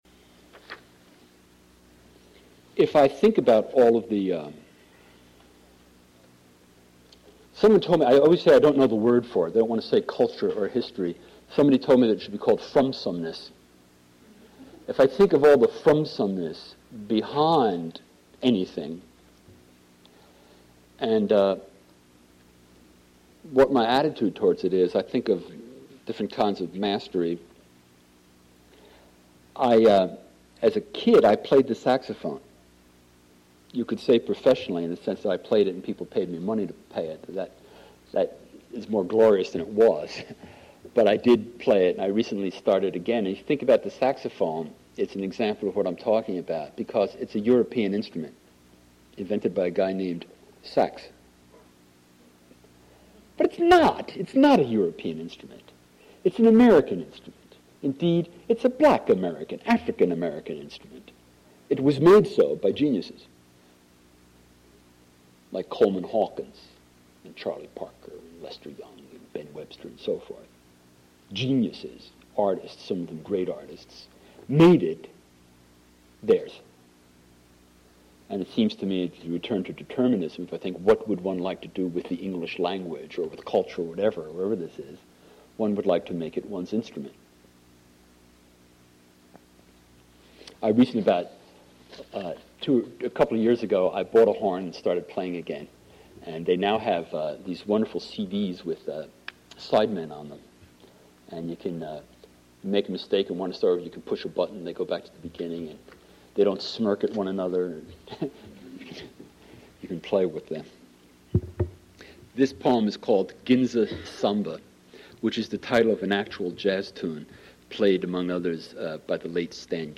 Reader
Reading